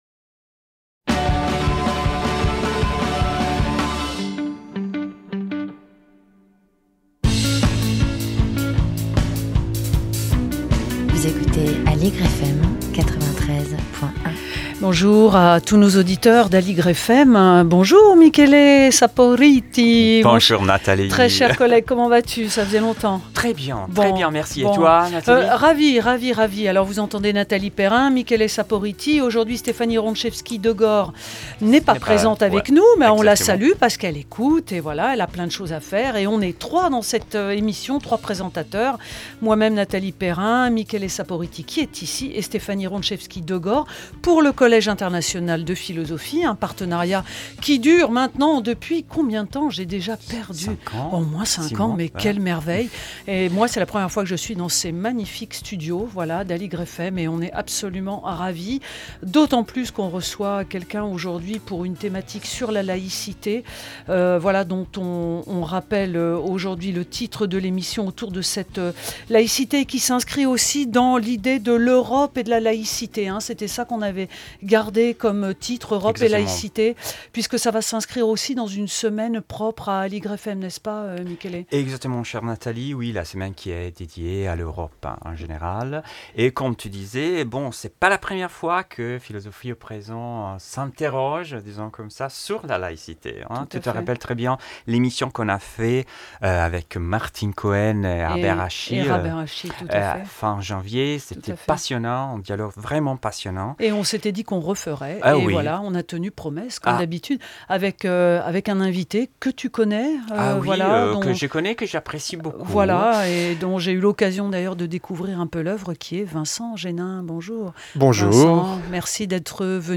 Aligre FM Philosophie au présent. Voix du Collège international de philosophie , émission mensuelle